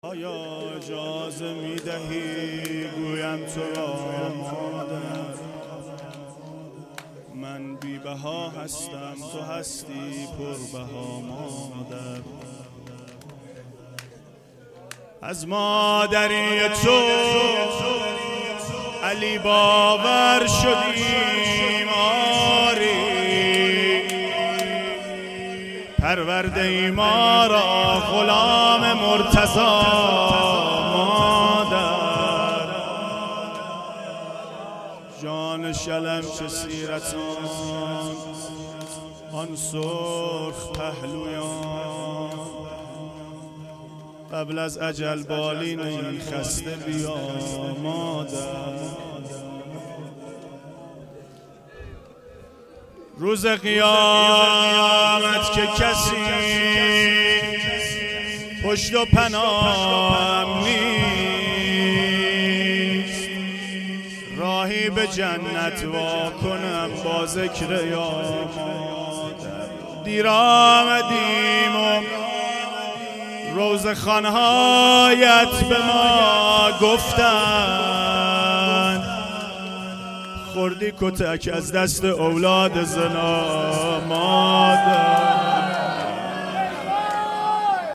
روضه پایانی شب پنجم فاطمیه